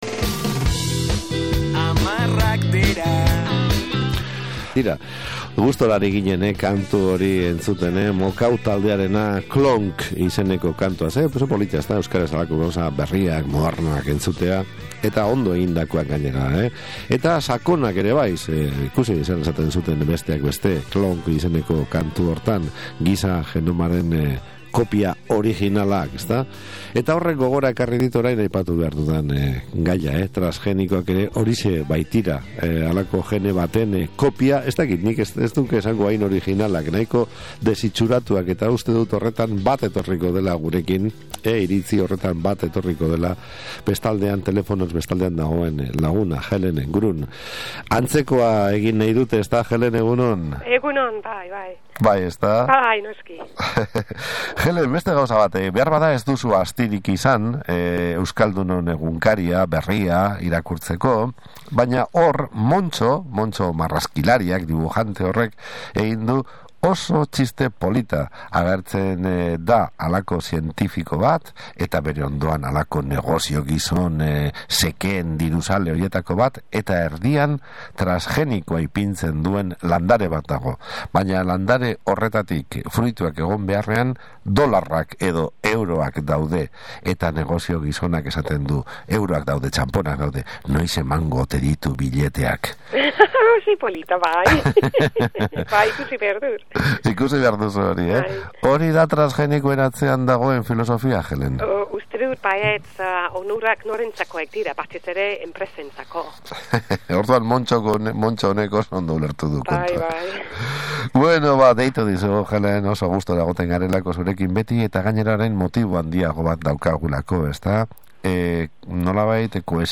SOLASALDIA: Transgenikoei buruzko dekretua
solasaldia